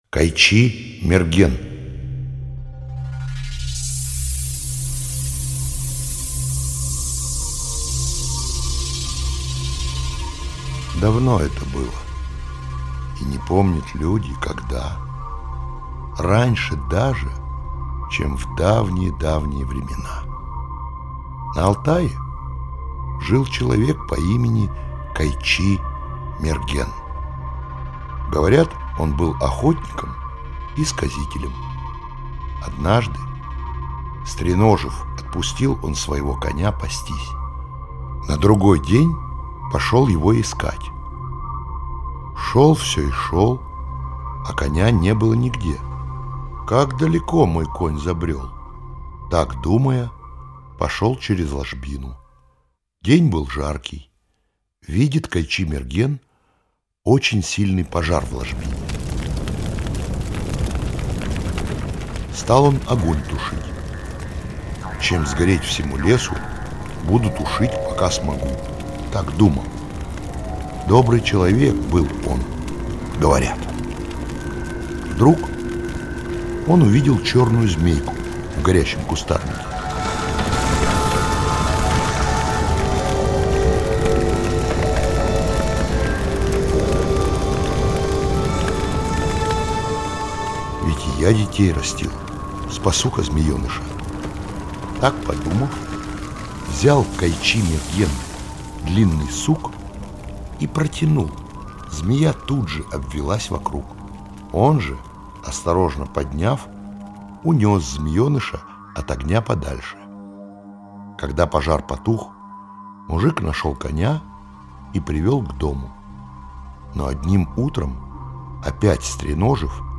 Кайчи-Мерген - алтайская аудиосказка - слушать онлайн